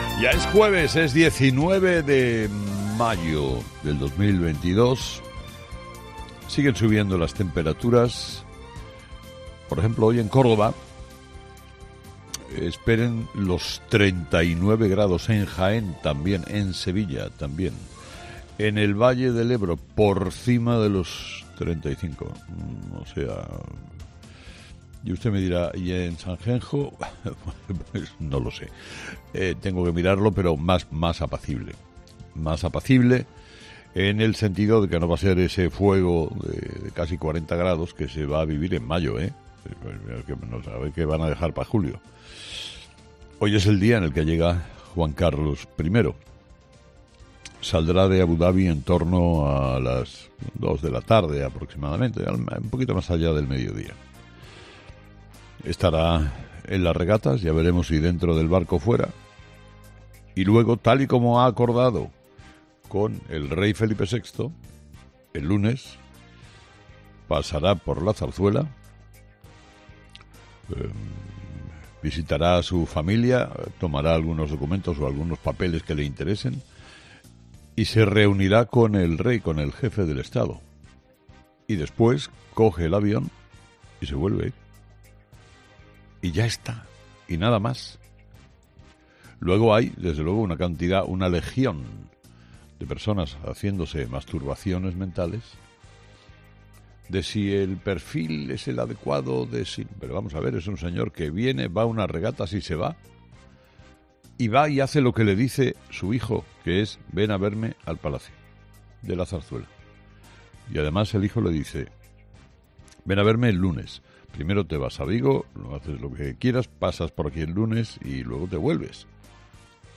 Carlos Herrera, director y presentador de 'Herrera en COPE', ha comenzado el programa de este miércoles analizando las principales claves de la jornada, que pasan, entre otros asuntos, por la llegada de Don Juan Carlos a España y lo ocurrido en la sesión de control al Gobierno en la jornada del miércoles.